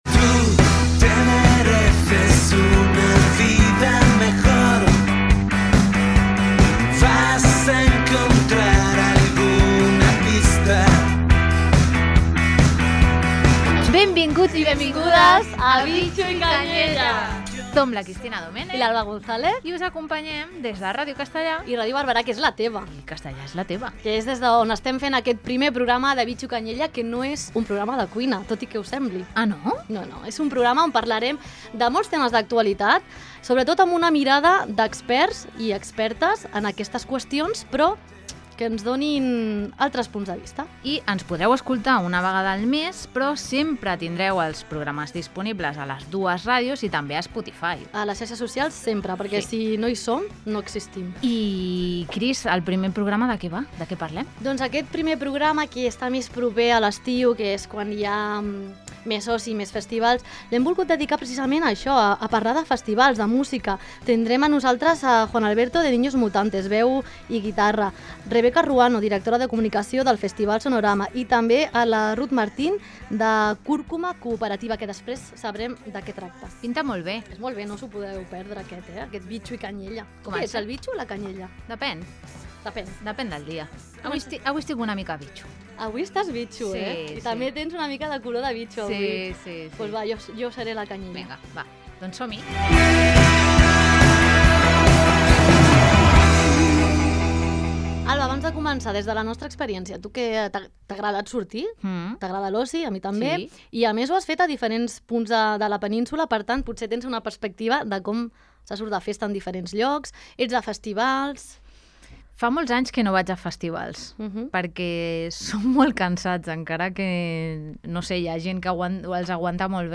Aquest primer programa de Bitxo i Canyella està dedicat a l'oci i a l'evolució dels festivals. Per parlar-ne, hem entrevistat